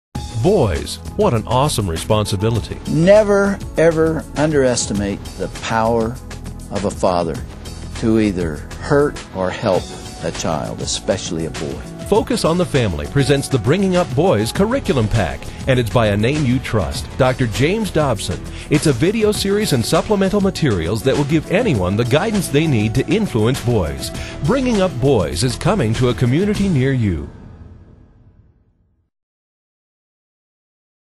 Audio Promotionals for Bringing Up Boys
These audio promos are taken from the promotional materials included in Dr. James Dobson's Bringing Up Boys Video Seminar